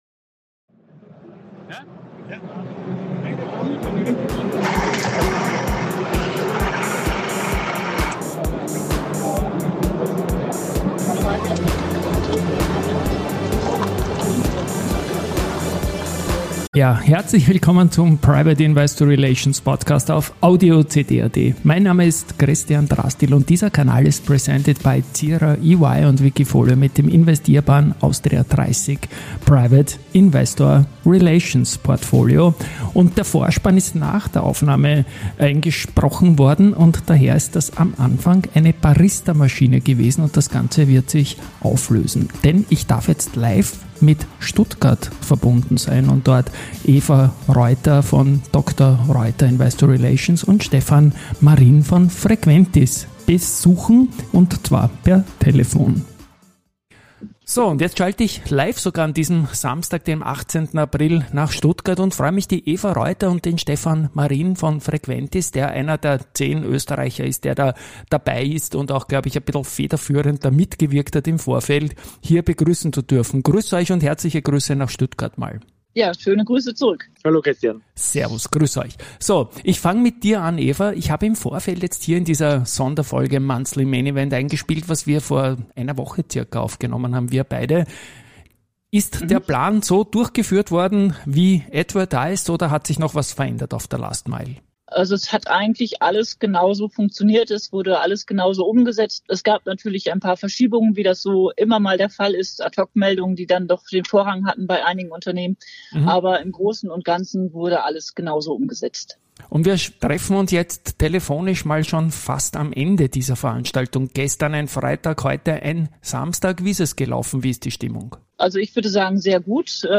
live von der Invest in Stuttgart